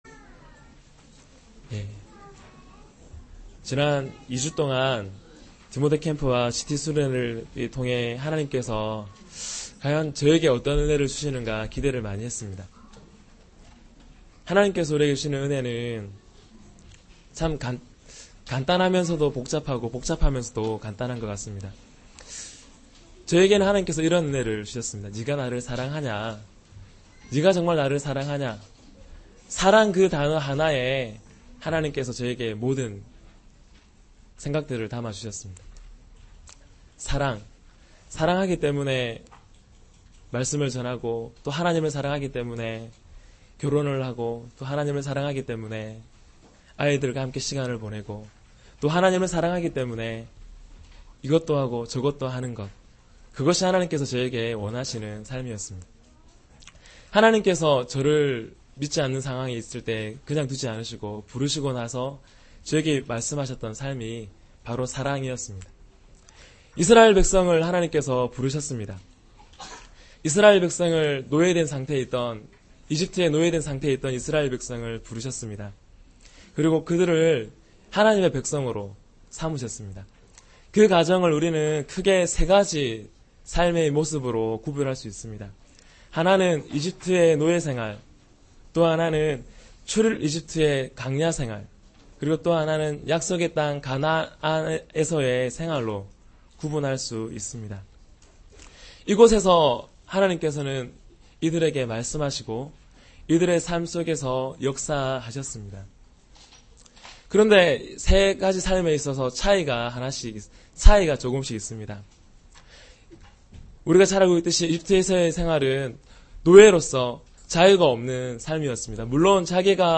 주일설교 - 10년 01월 31일 "우리앞에 있는 경주를 열심히 달려갑시다"(히12:1-3)-강도사님